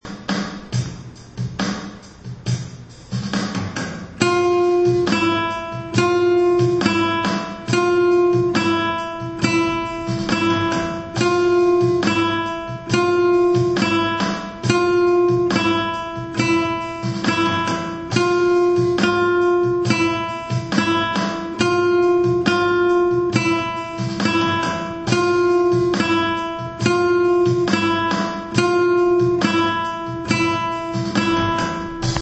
گیتار, آموزش